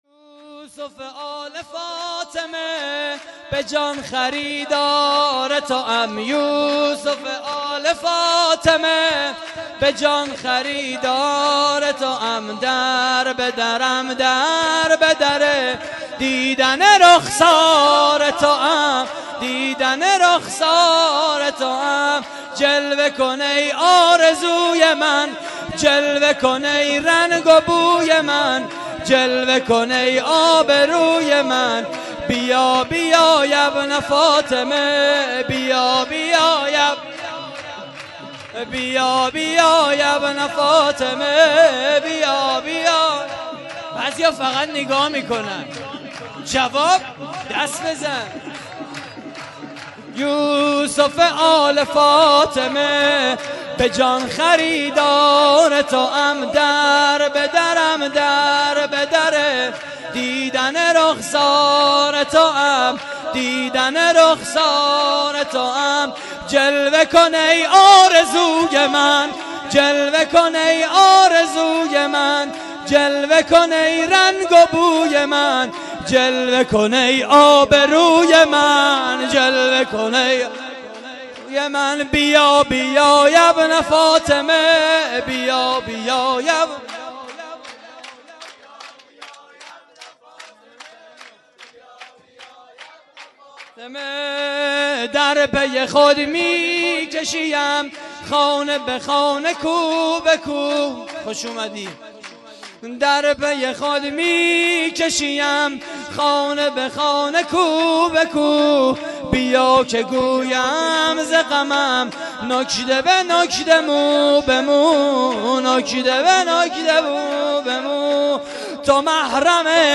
صوت مراسم:
شور: بیا بیا یابن فاطمه؛ پخش آنلاین |